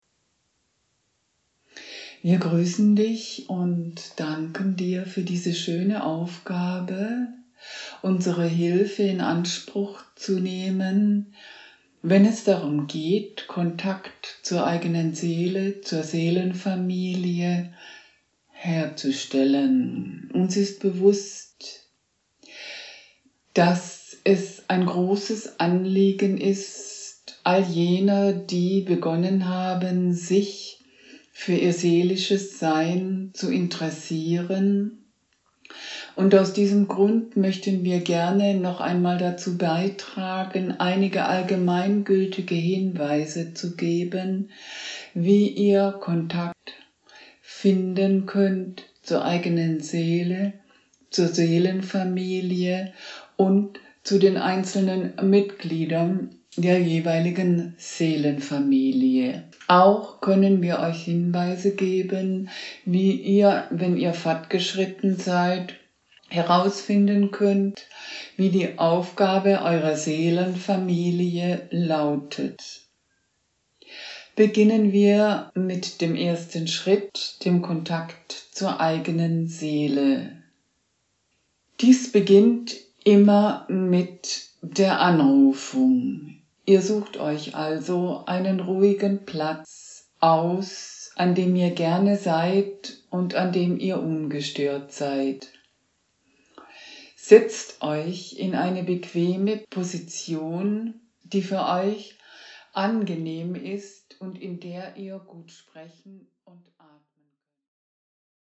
gechannelte Anleitung